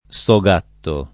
sogatto [ S o g# tto ] (anche soatto [ S o # tto ] o sovatto [ S ov # tto ] o sugatto [ S u g# tto ])